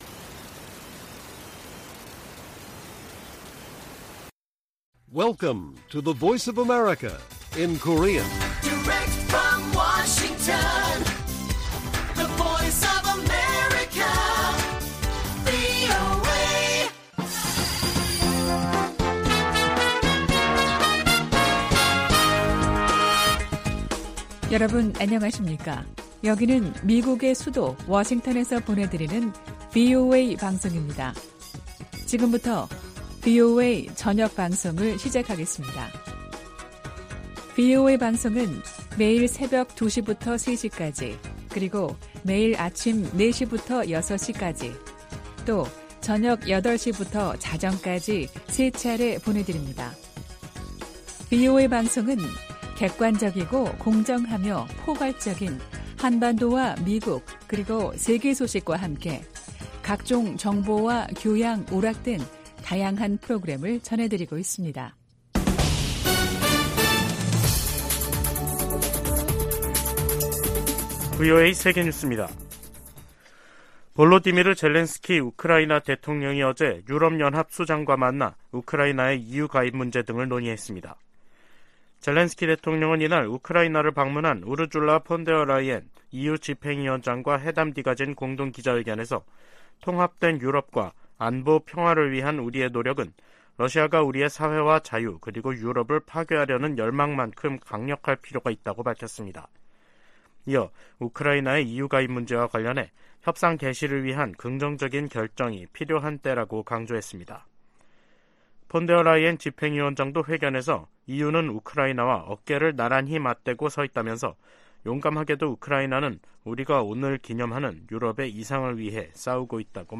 VOA 한국어 간판 뉴스 프로그램 '뉴스 투데이', 2023년 5월 10일 1부 방송입니다. 미 국무부는 ‘미한일 미사일 정보 실시간 공유 방침’ 관련 일본 언론 보도에 대해, 비공개 외교 대화 내용을 밝히지 않는다면서도, 3각 공조는 필수라고 강조했습니다.